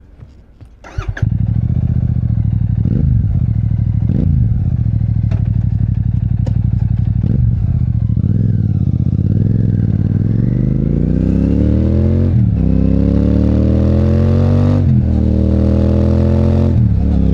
Experience the roar!
Built specifically for the Classic 650 Twin, this exhaust transforms your riding experience — with a deeper growl, stronger pull, and sharper throttle response.
• Signature Deep Exhaust Note: A rich, throaty sound that commands attention — without overpowering your ride.
ExhaustSound_V1.mp3